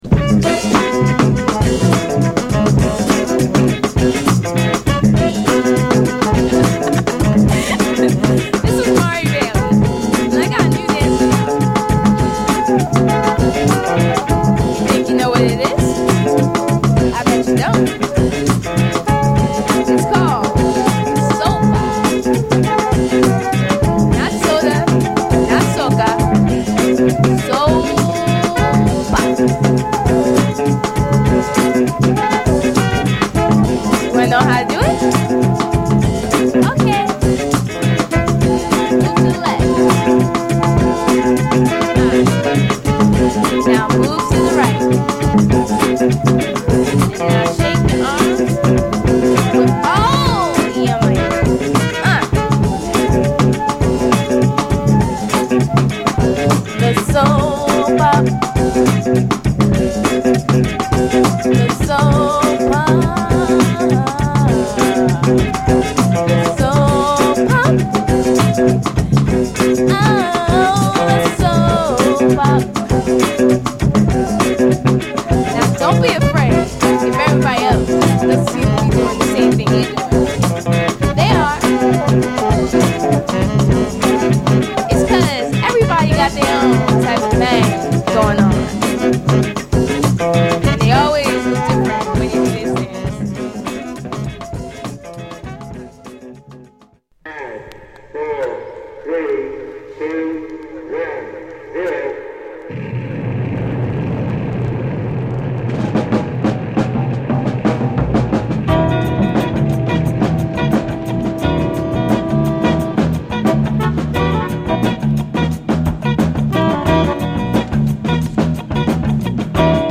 中盤にはドラムブレイクも搭載！